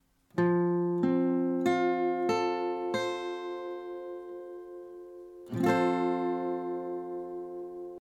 F-Dur (Barré, A-Saite)
Hier ist es besonders wichtig, die E-Saite mit der Spitze des Zeigefingers abzudämpfen.
F-Dur-Barre-A.mp3